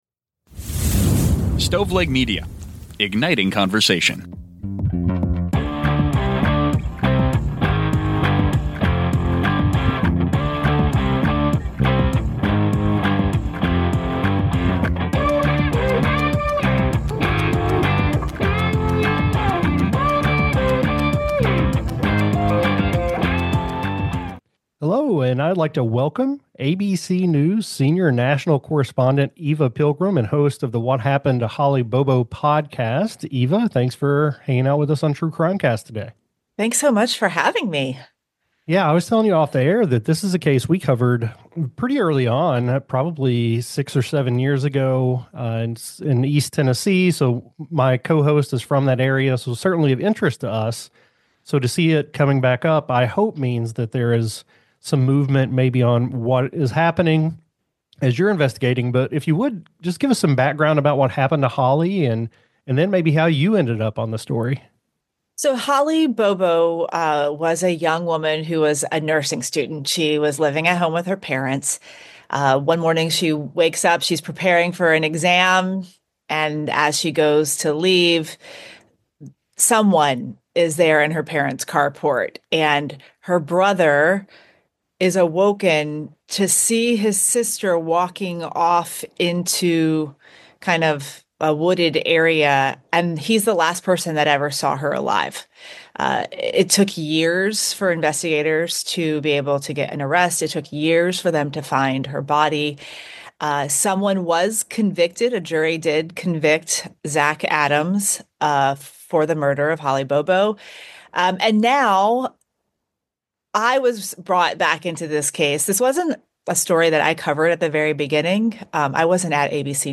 Society & Culture, Personal Journals, Documentary, True Crime